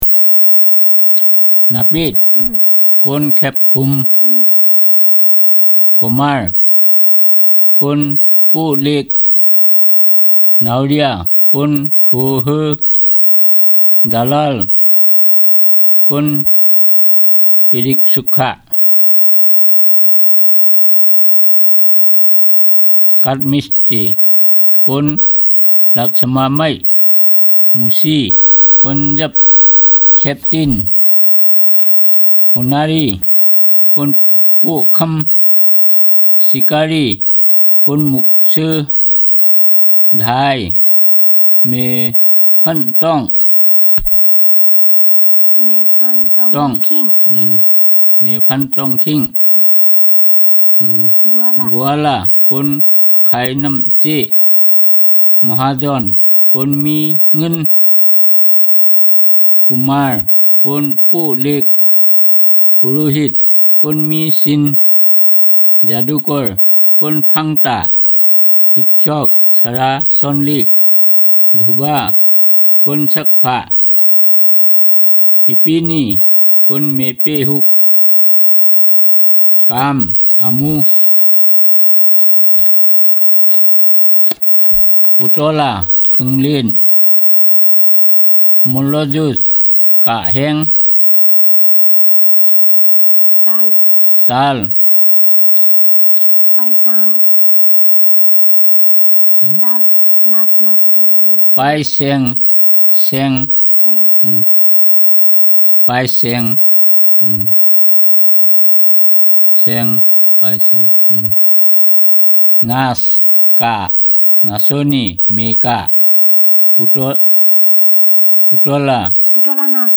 Elicitation of words about occupation and related, adjectives, color terms, shape, time, directions, and seasons